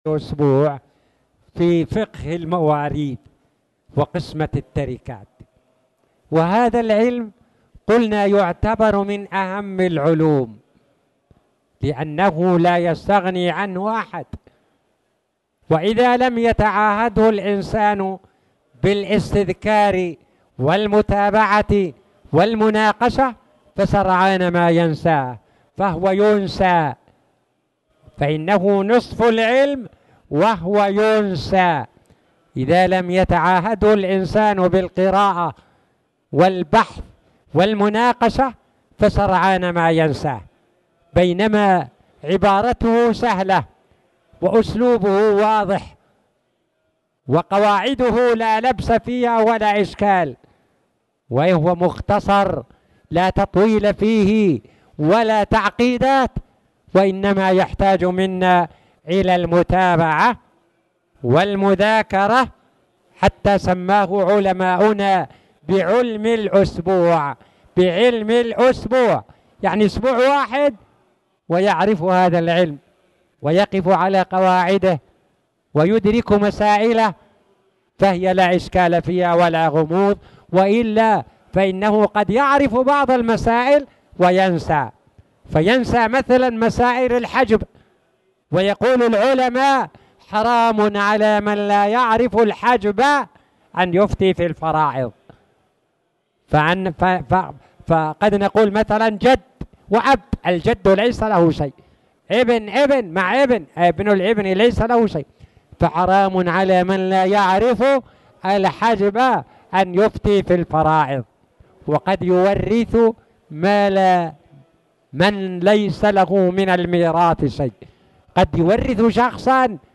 تاريخ النشر ١١ ربيع الثاني ١٤٣٨ هـ المكان: المسجد الحرام الشيخ